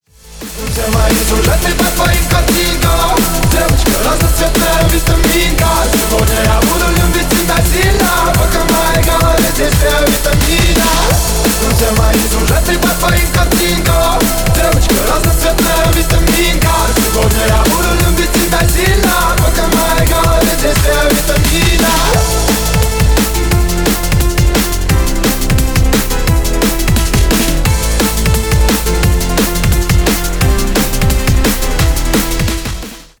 Ремикс # Поп Музыка
ритмичные